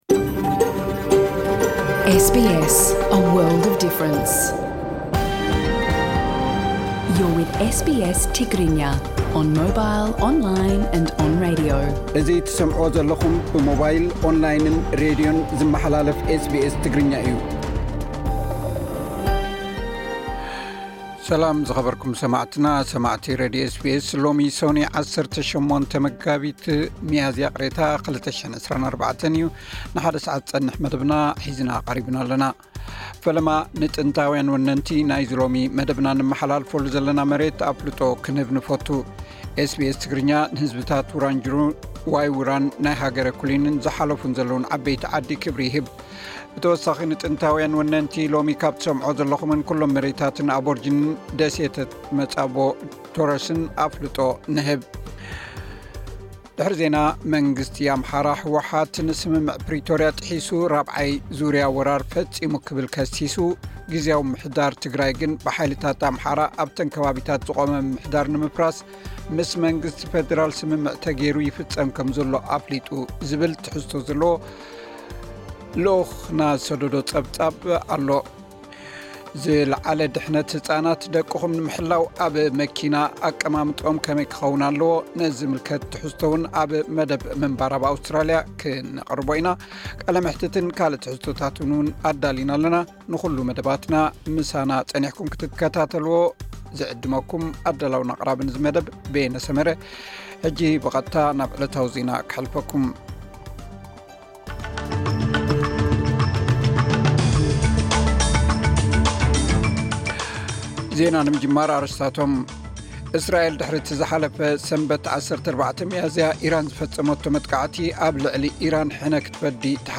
ዜናታት ኤስ ቢ ኤስ ትግርኛ (18 ሚያዝያ 2024)